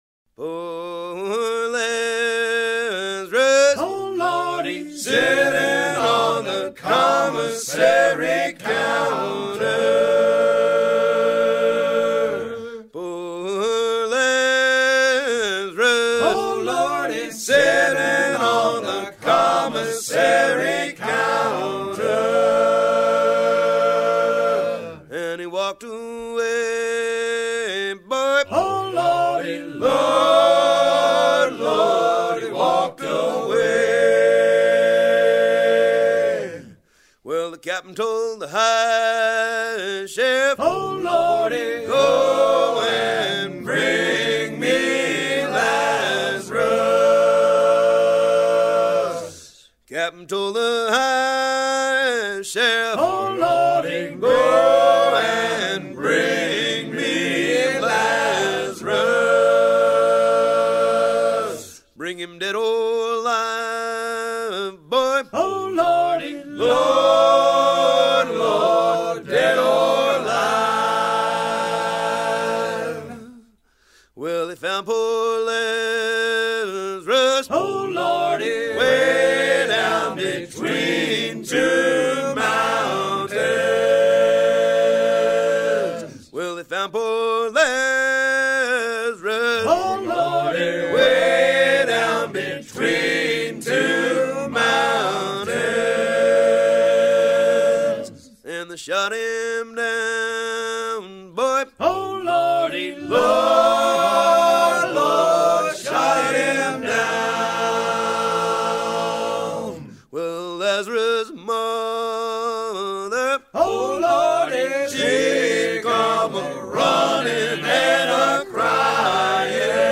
à haler les filets
maritimes
Pièce musicale éditée